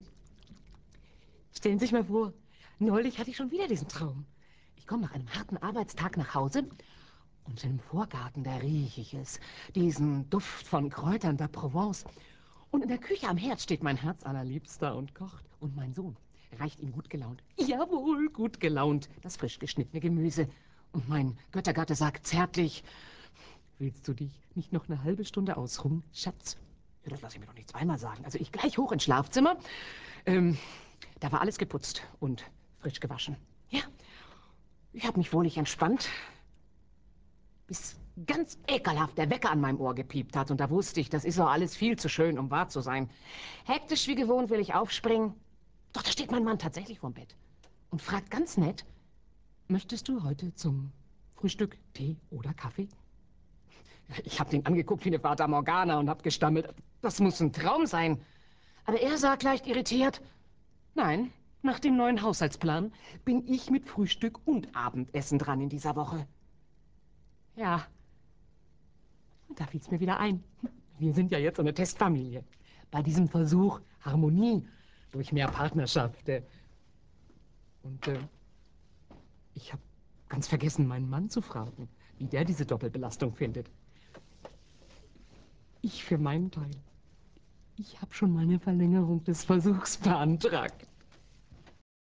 Vision einer Gleichberechtigungstestfamilie. Eine Frau erzählt von den traumhaften Bedingungen zuhause, seitdem sie als Testfamilie des Programms "Harmonie durch mehr Partnerschaft" teilnehmen.